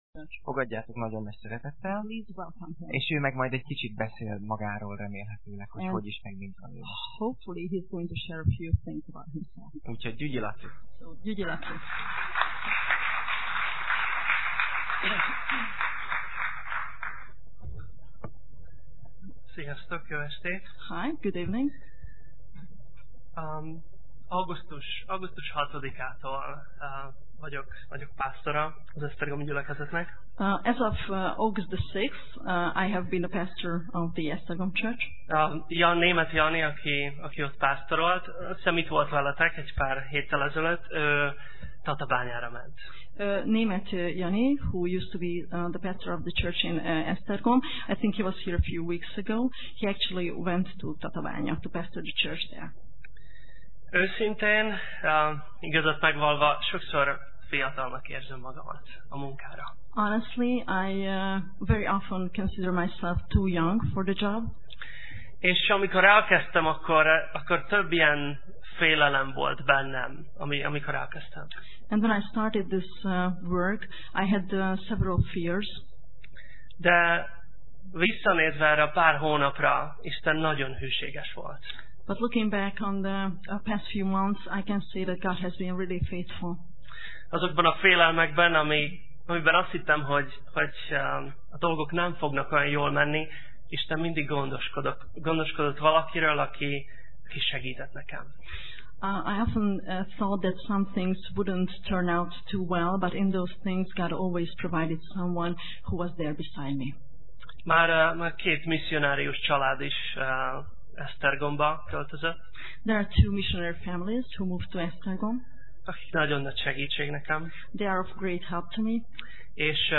Tematikus tanítás Alkalom: Szerda Este